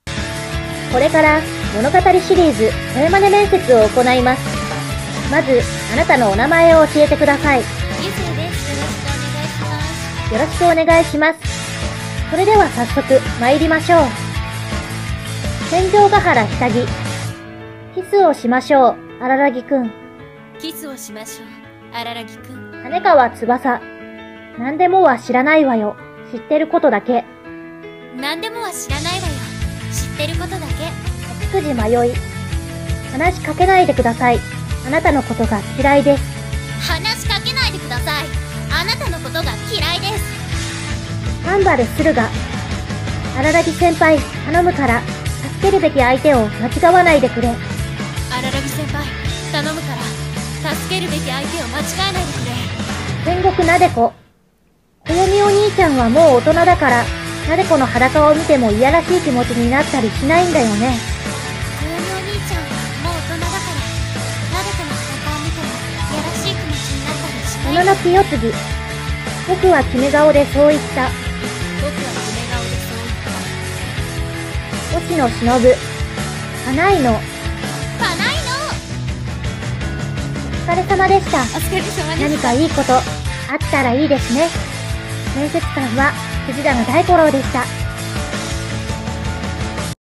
物語シリーズ声真似面接【声真似】